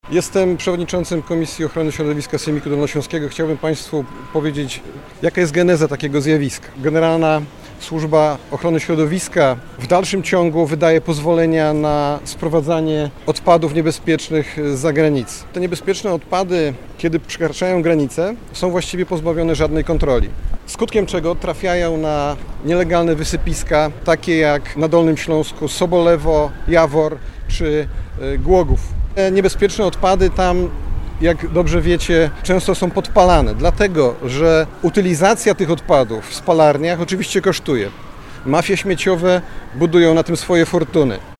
W te wakacje głośno było o „aferze odpadowej” – nielegalne odpady z Niemiec wciąż zalegają na składowiskach w Sobolewie, Starym Jaworze czy Głogowie. – tłumaczy Ryszard Lech, radny Sejmiku Województwa.